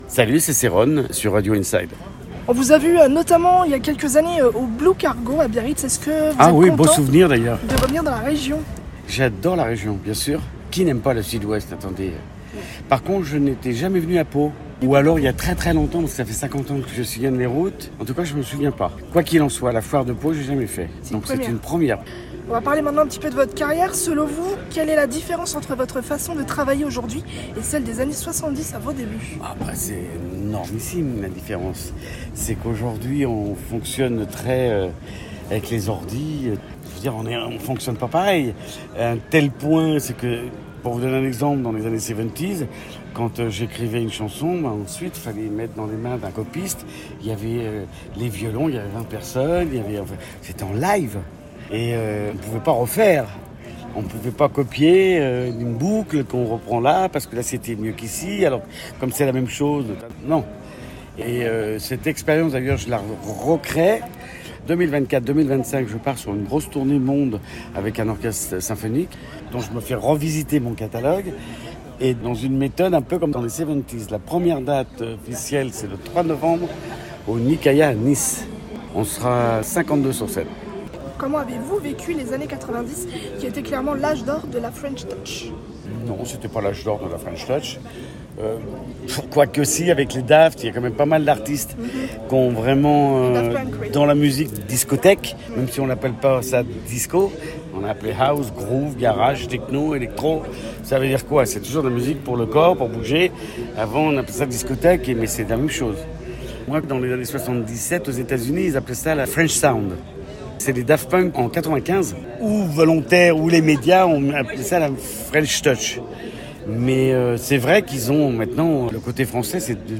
Interview de "Cerrone" la Foire de Pau 2023